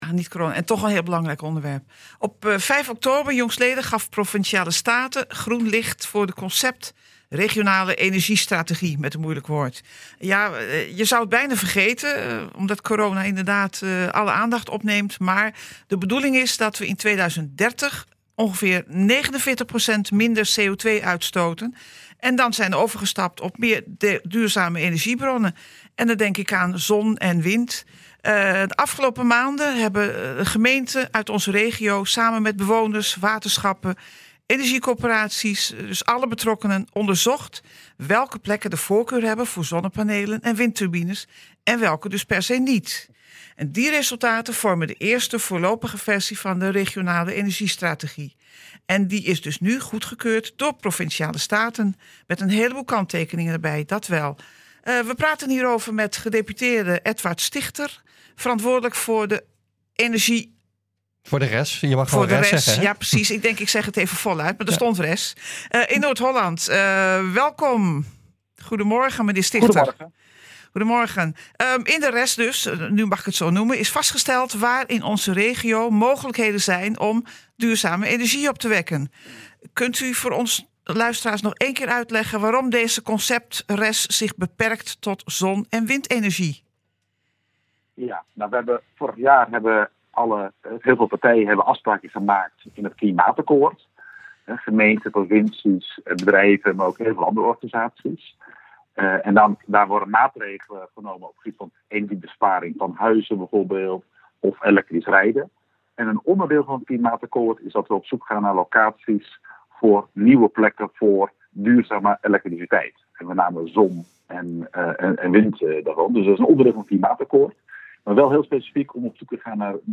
We praten erover met gedeputeerde Edward Stigter, verantwoordelijk voor de RES in Noord Holland.